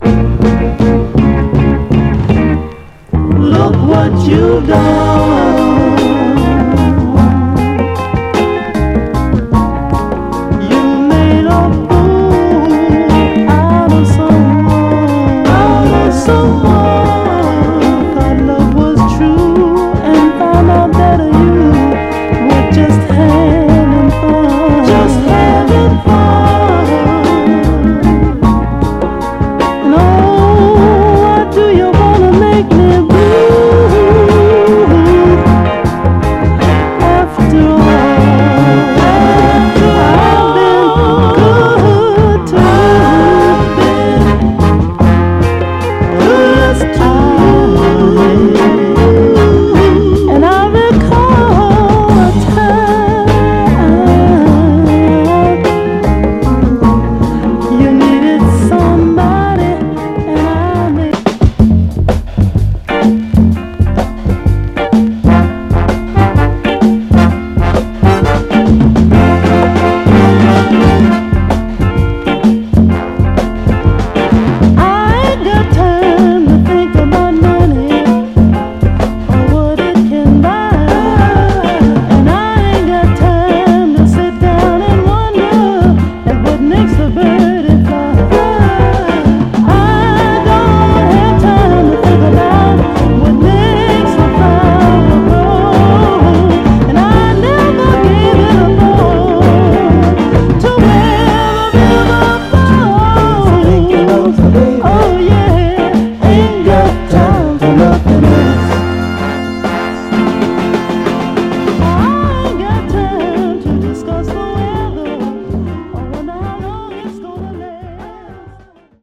タフなヴァイナル・プレス。
序盤でチリつきますが、それ以降は見た目の割にノイズは少なくプレイ概ね良好です。
※試聴音源は実際にお送りする商品から録音したものです※